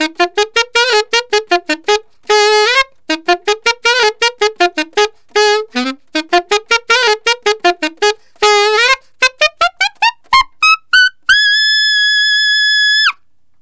Alto One Shot in Ab 02.wav